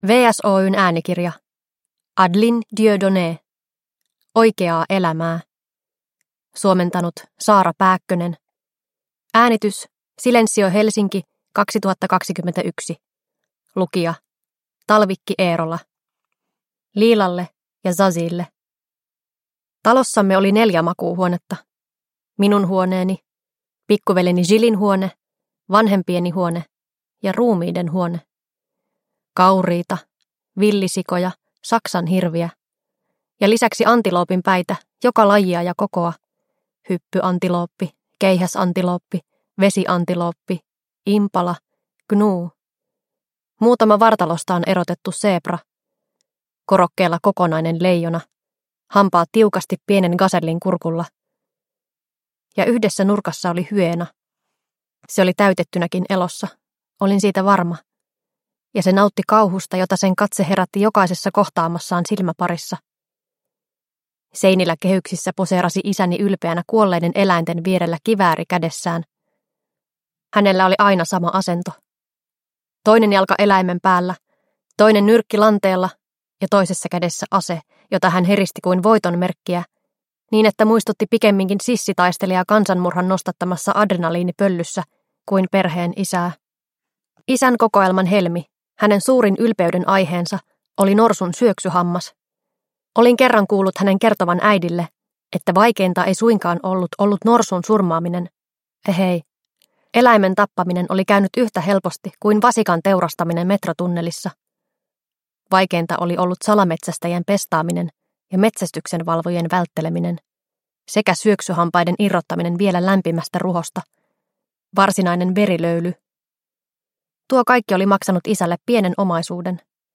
Oikeaa elämää – Ljudbok – Laddas ner